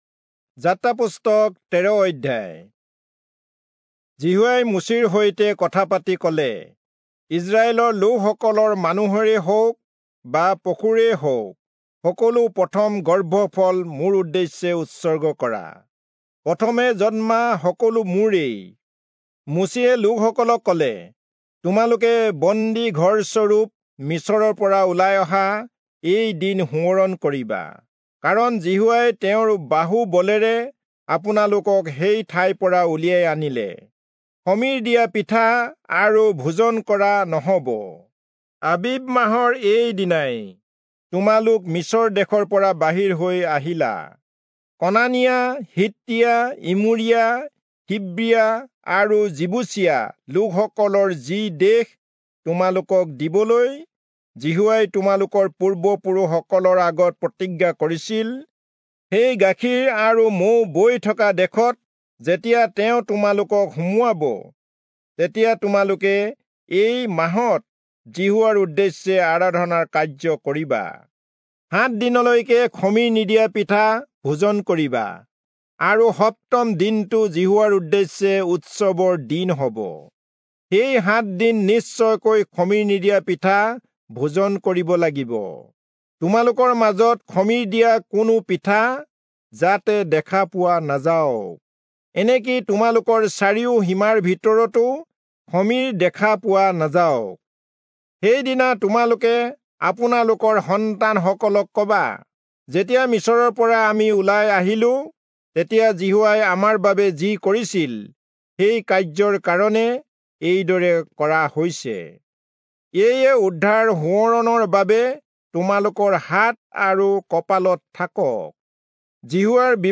Assamese Audio Bible - Exodus 7 in Litv bible version